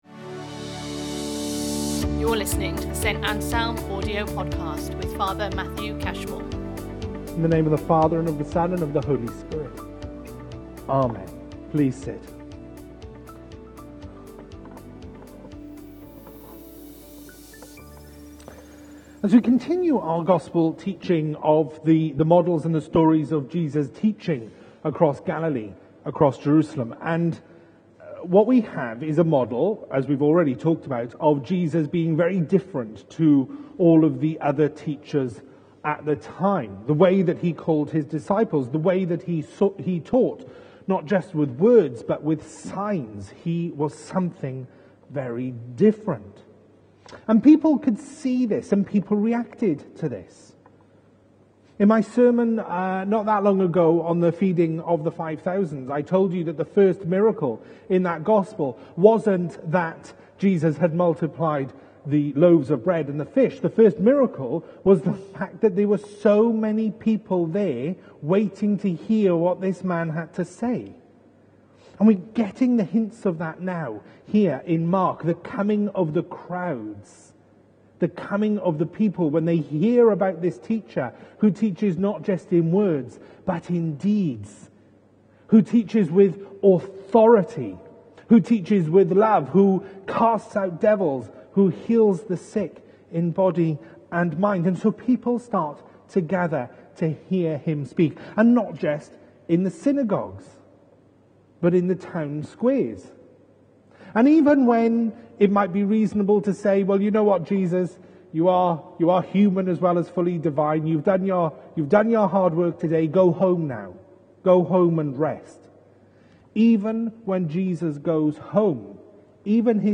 Prayer Series Sunday Sermons Book Mark Watch Listen Read Save Mark 1:29-39 My question as you go out into the world this week is, what can you do?